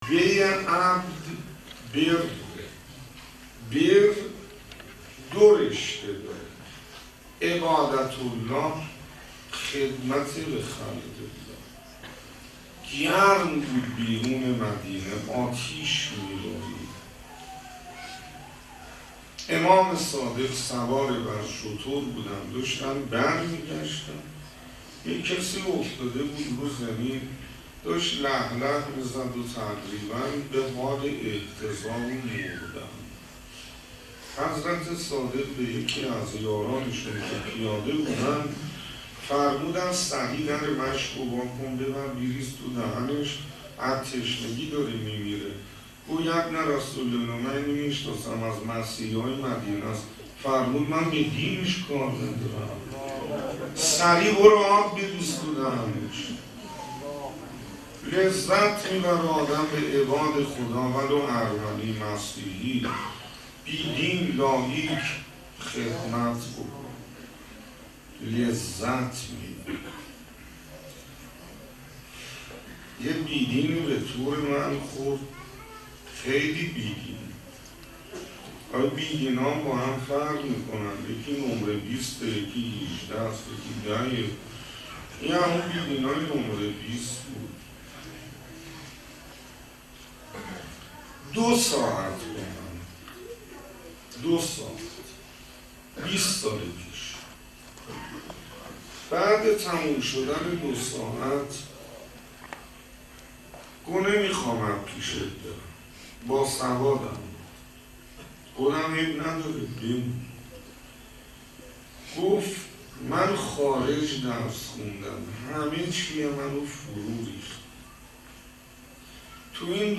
برچسب ها سخنرانی ترکی سخنرانی آذری شیخ حسین انصاریان
سخنرانی